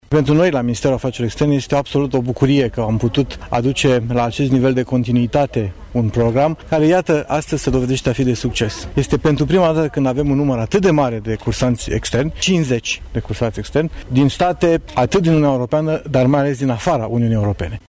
Evenimentul a cuprins oficierea unui Te Deum, depunerea de coroane de flori și susținerea de alocuțiuni. La eveniment, pe lângă oficilități locale și județene, a fost prezent și Dan Neculaescu, ministru secretar de stat în Ministerul Afacerilor Externe: